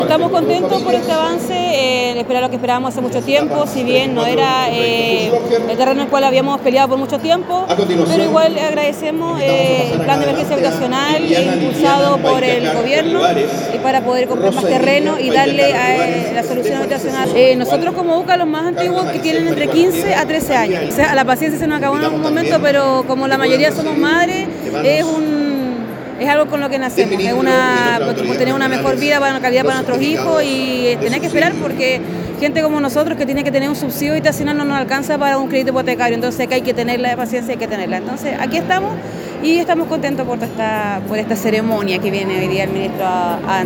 En una emotiva ceremonia realizada este martes en el Gimnasio María Gallardo de Osorno, 940 familias pertenecientes a los proyectos habitacionales Lomas de Ovejería II y Alberto Fuchslocher recibieron sus subsidios habitacionales.